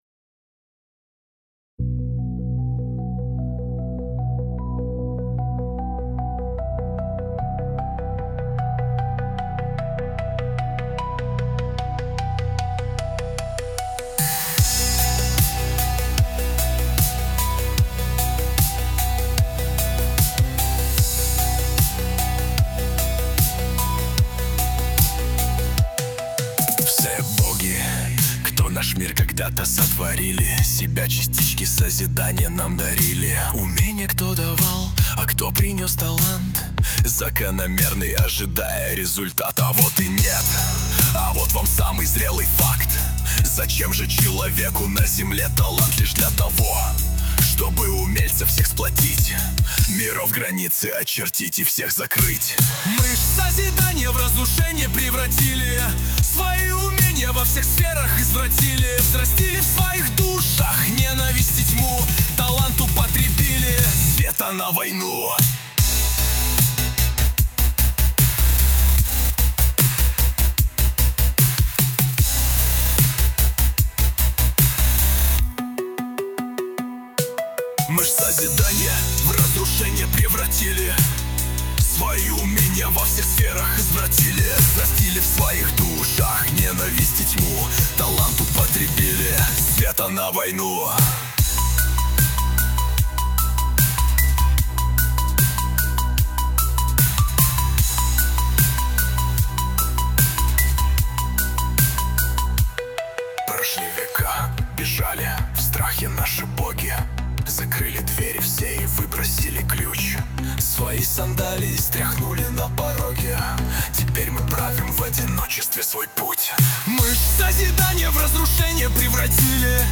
mp3,4334k] Рок